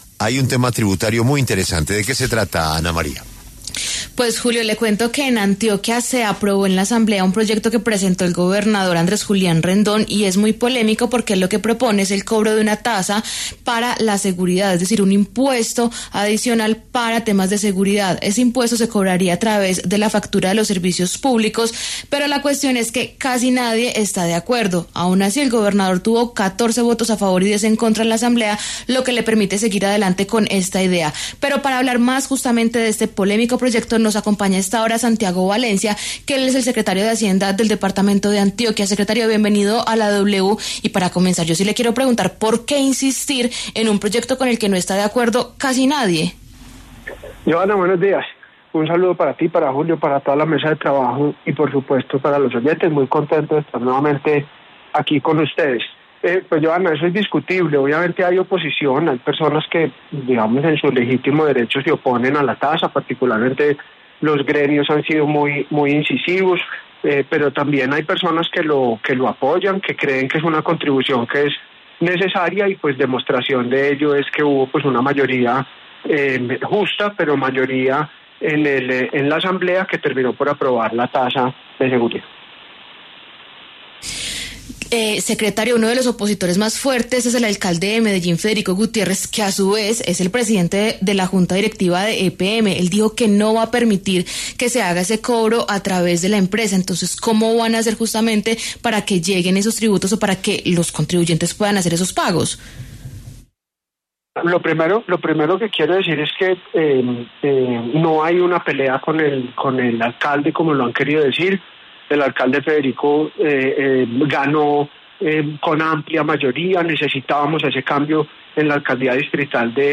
Santiago Valencia, secretario de Hacienda, explicó en La W los detalles jurídicos de la medida.